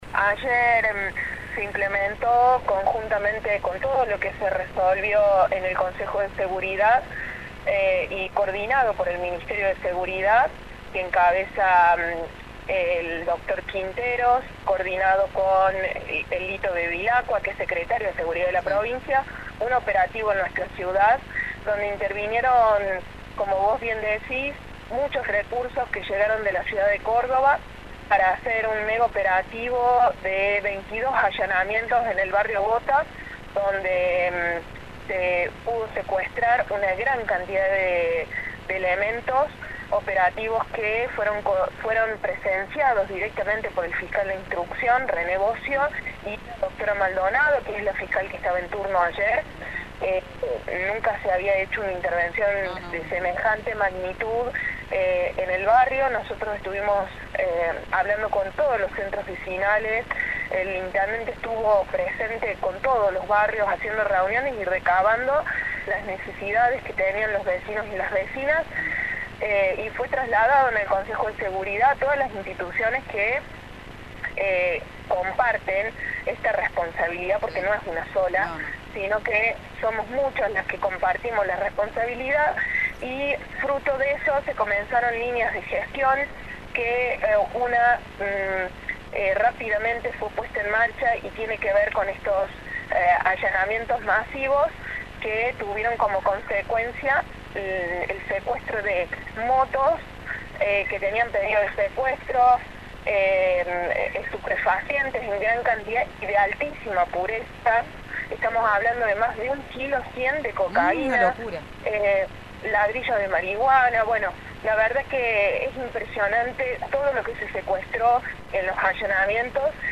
Guadalupe Vázquez, secretaria de Prevención Comunitaria, Seguridad y Convivencia Urbana, habló con Radio Show y brindó detalles.